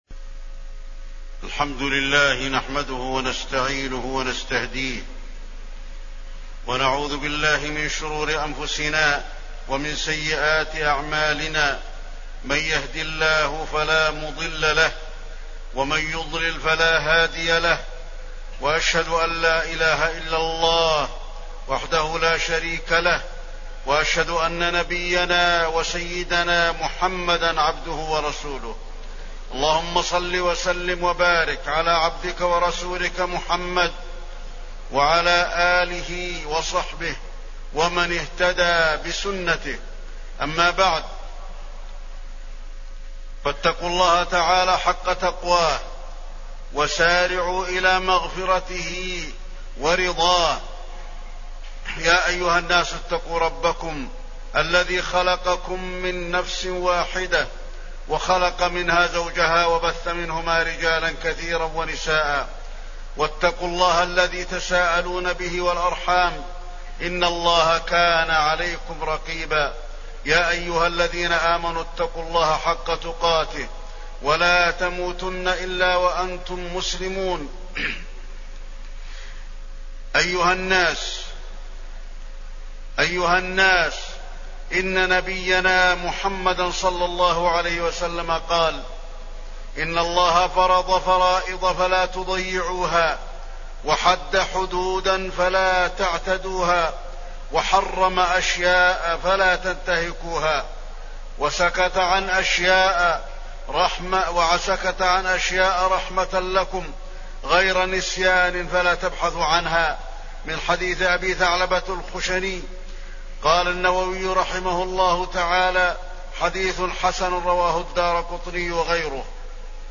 تاريخ النشر ٧ ربيع الثاني ١٤٣٠ هـ المكان: المسجد النبوي الشيخ: فضيلة الشيخ د. علي بن عبدالرحمن الحذيفي فضيلة الشيخ د. علي بن عبدالرحمن الحذيفي الأمر بالمعروف والنهي عن المنكر